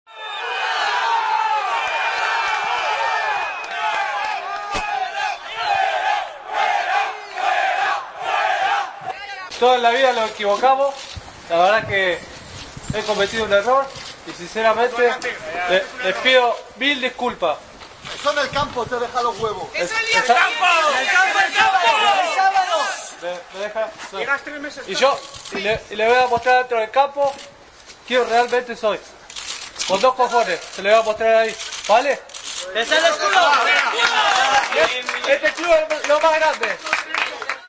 Culio, abucheado en su presentación con el Real Zaragoza